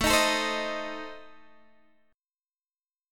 A Diminished